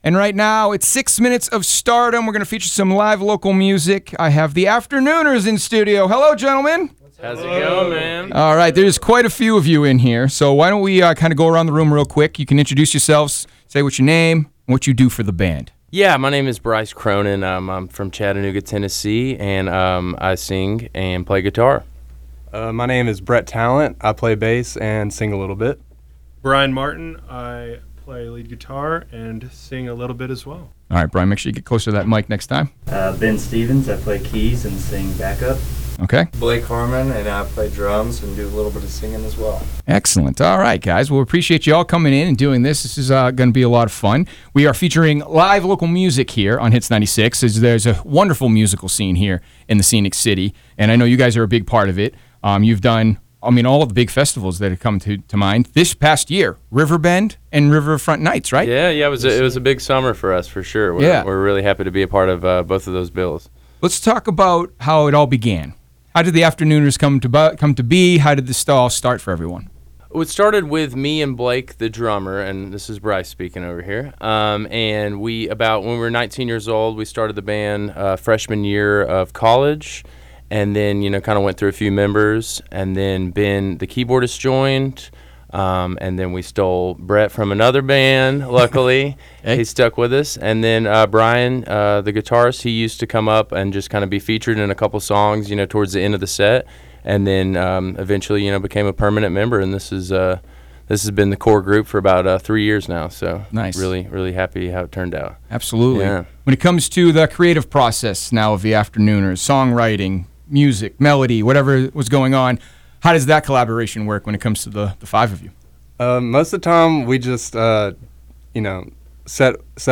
Full-Interview-The-Afternooners.wav